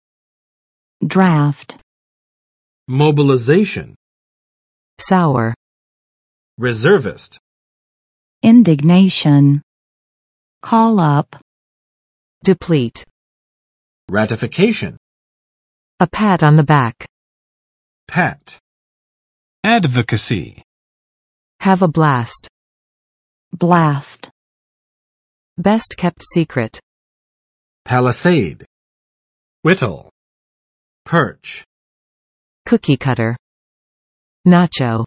[dræft] n.【美】征兵，征集